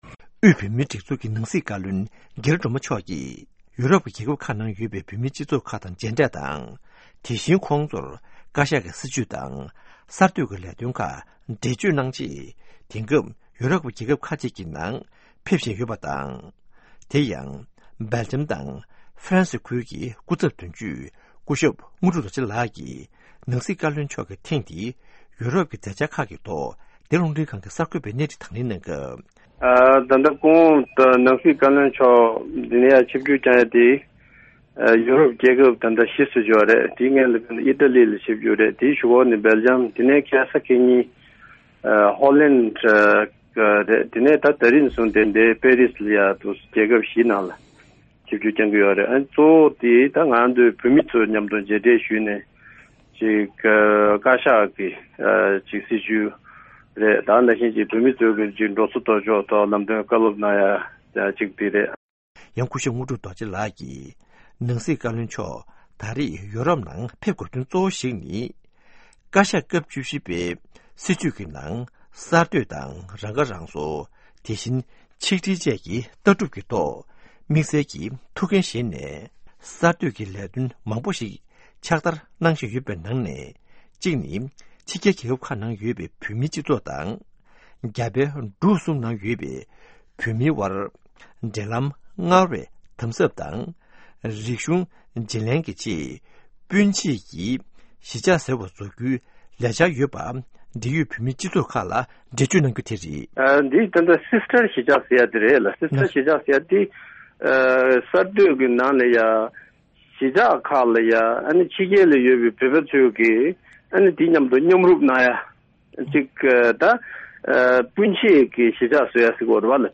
གནས་འདྲི་ཞུས་ཏེ་གནས་ཚུལ་ཕྱོགས་བསྒྲིགས་གནང་བ་ཞིག་གསན་གྱི་རེད།